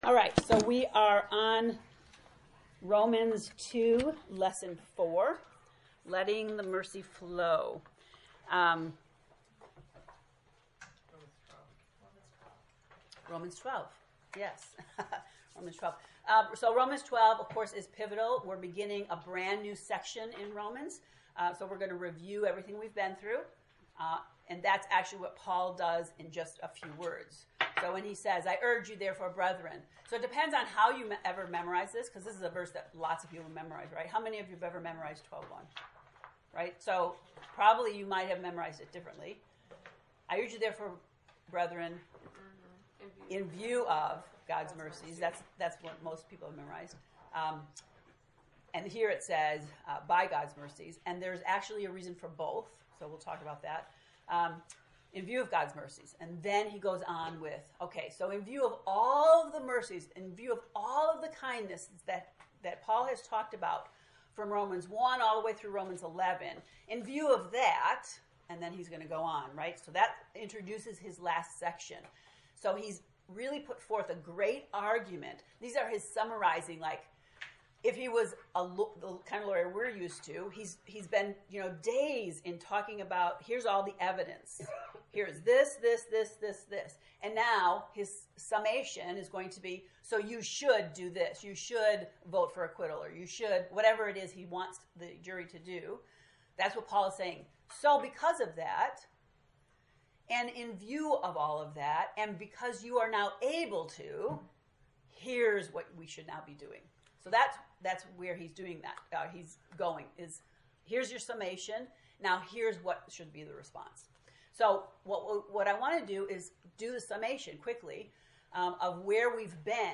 To listen to the lesson 4 lecture, “Letting the Mercy Flow,” click below: